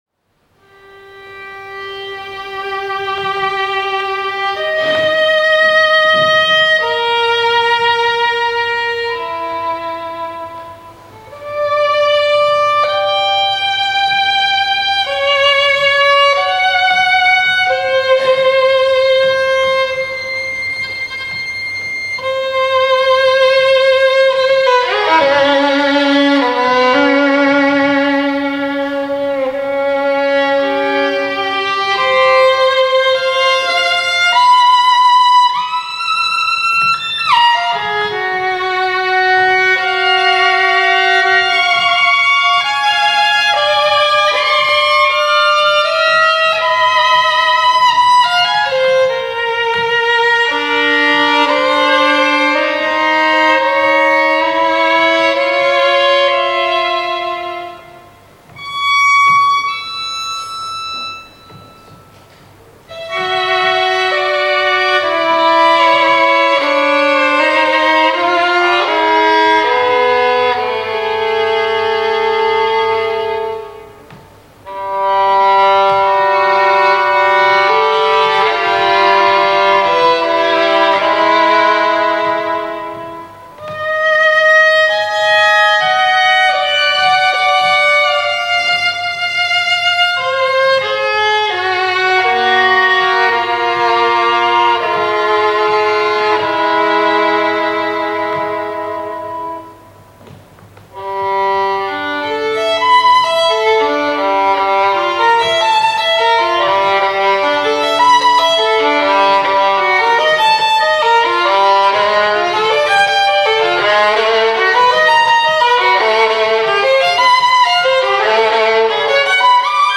pour violon seul
for solo violin